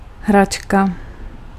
Ääntäminen
IPA : /tɔɪ/ US : IPA : [tɔɪ]